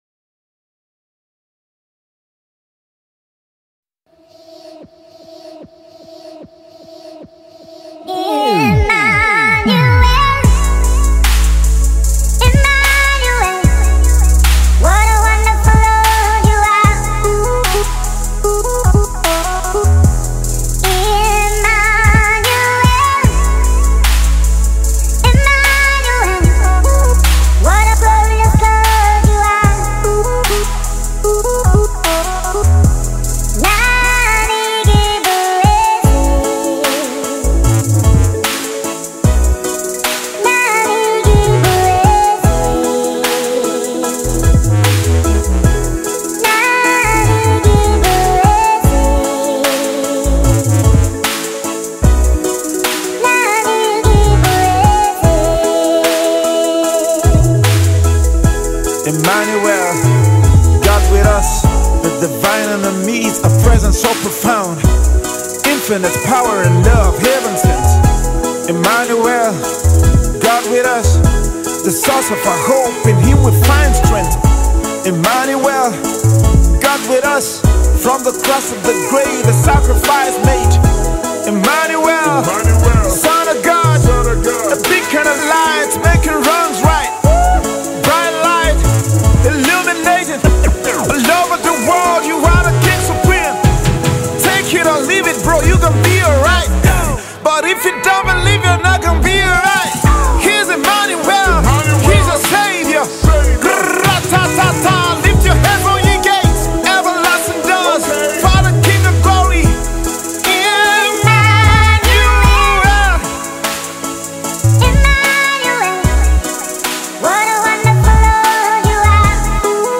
Afro Gospel